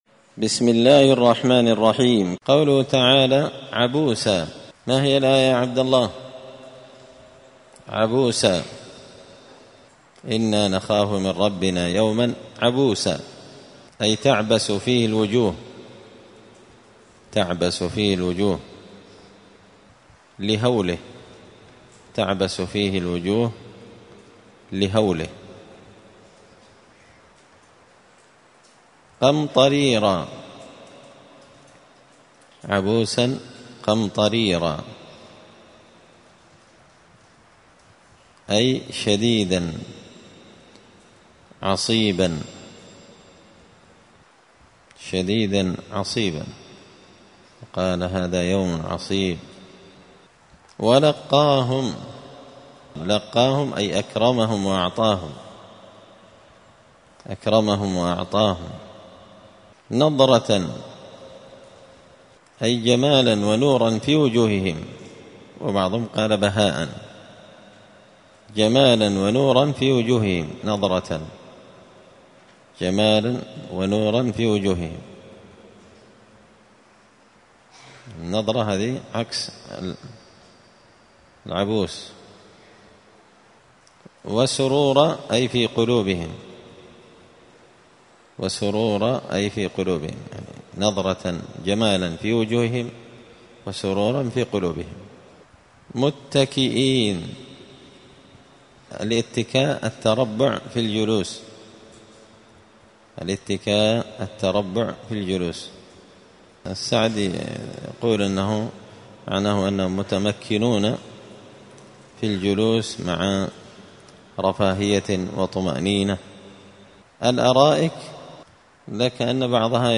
الثلاثاء 18 ربيع الأول 1445 هــــ | الدروس، دروس القران وعلومة، زبدة الأقوال في غريب كلام المتعال | شارك بتعليقك | 77 المشاهدات